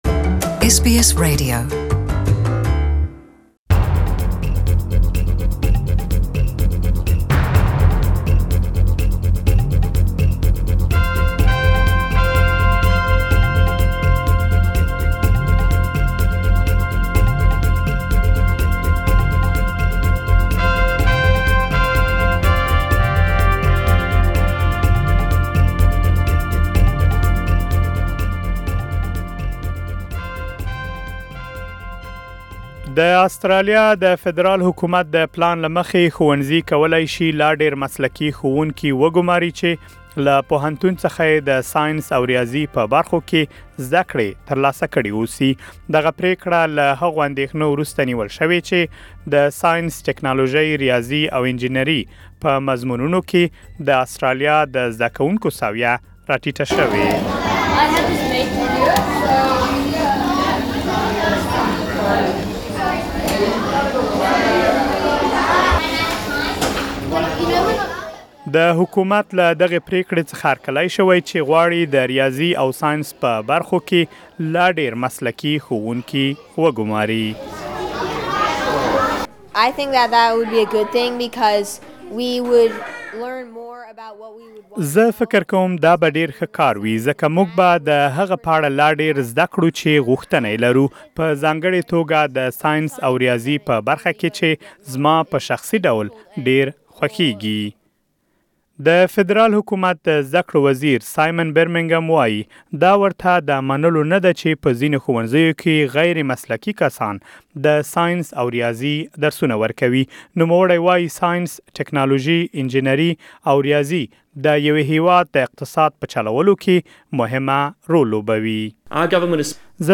Schools could get more specialist teachers who studied Maths and Science at university under a new Federal government plan. It comes after increasing concerns about the decline of Australian student performance in the so-called STEM subjects - science, technology, engineering and maths. For more details, Please listen to the full report.